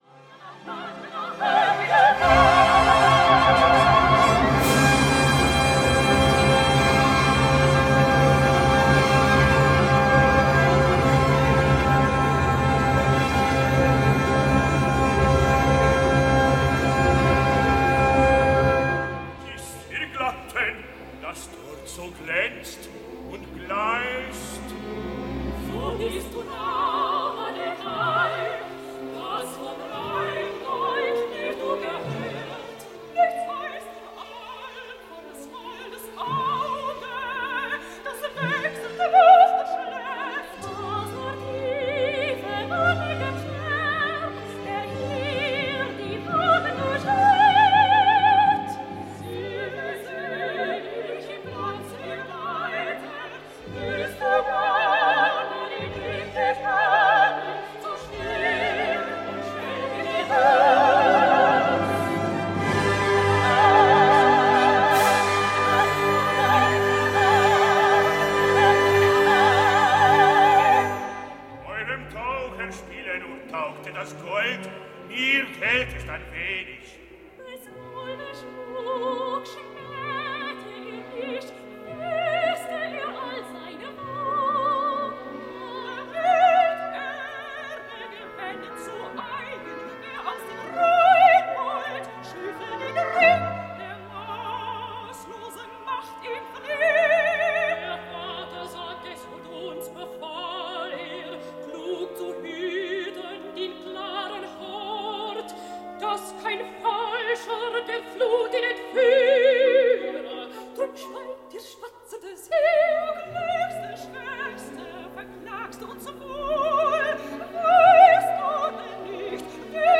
Loriot erzählt Richard Wagners Ring des Nibelungen (Remastered) - Richard Wagner - Hörbuch